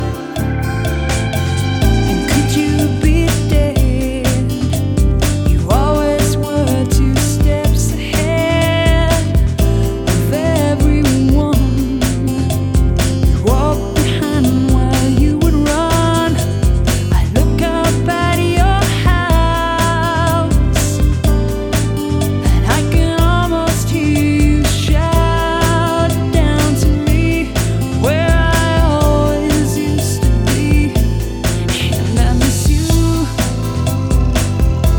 Жанр: Поп музыка / Рок / Танцевальные / Альтернатива